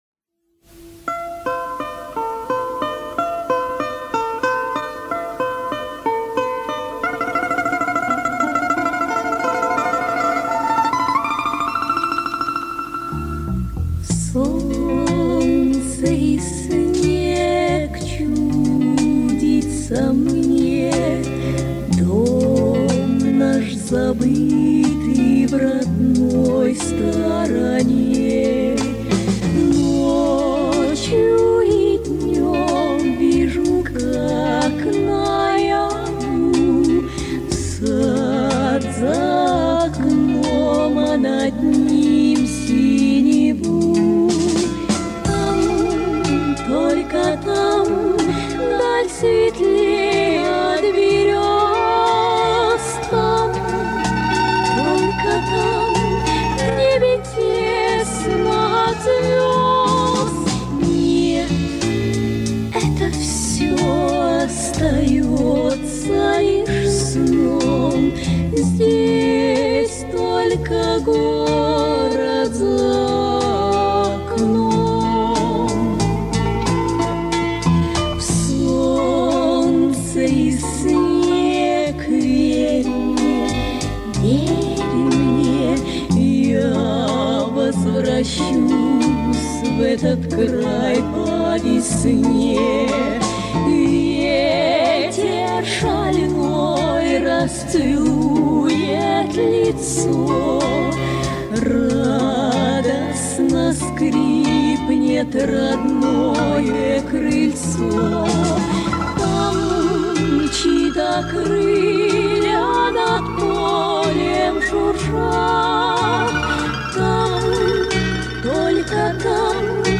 Нашел эту запись на кассете